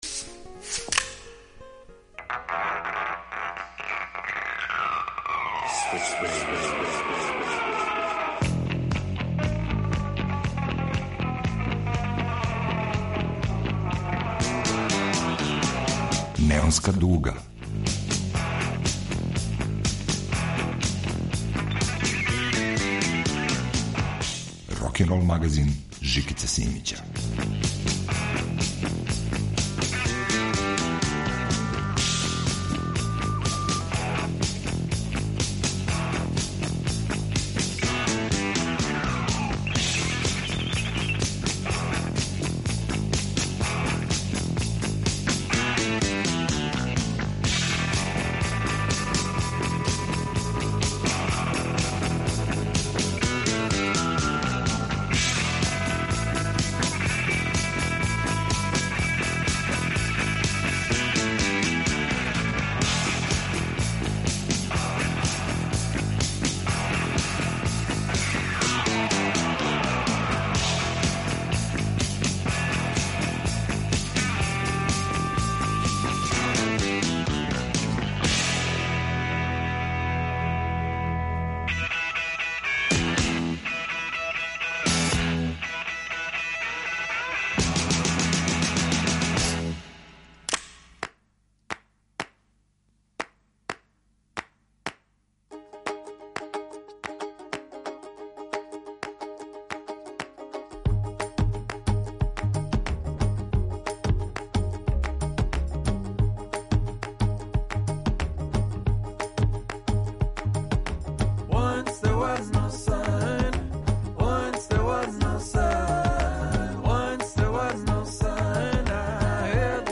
Вратоломни сурф кроз време и жанрове.
Још један осврт на овогодишња рокерска збивања. Имагинарни „јесењи оркестар", са еклектичним репертоаром, одсвираће преко 20 песама. Од тексаског блуза до панонских елегија.